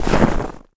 High Quality Footsteps
STEPS Snow, Run 26.wav